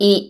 Italy, fit, kiss
_ i